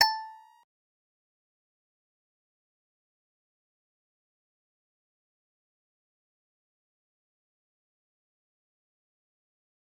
G_Musicbox-A5-pp.wav